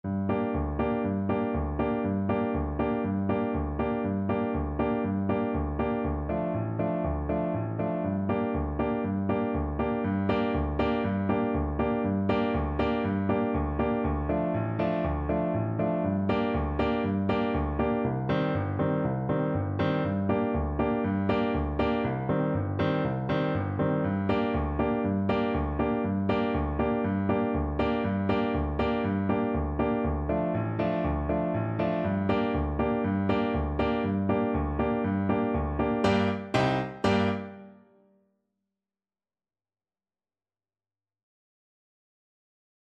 Allegro vivo (View more music marked Allegro)
4/4 (View more 4/4 Music)
World (View more World Saxophone Music)